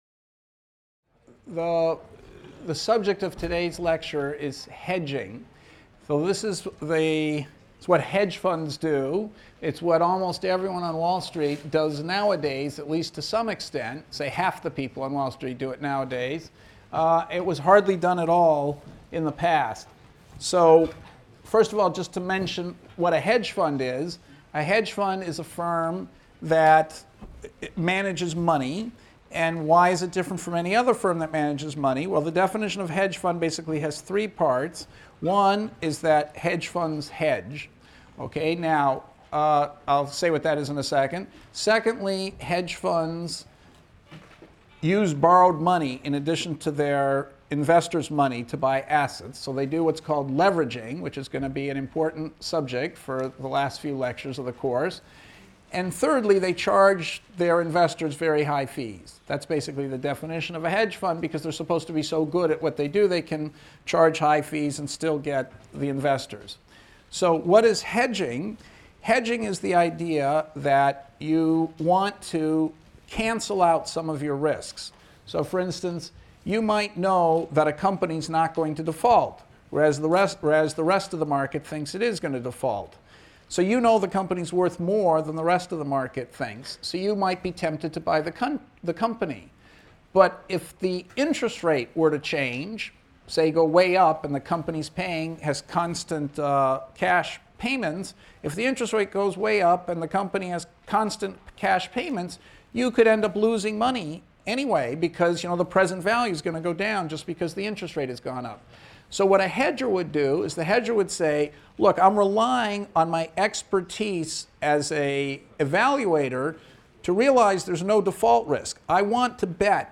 ECON 251 - Lecture 20 - Dynamic Hedging | Open Yale Courses